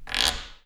frog_sound.wav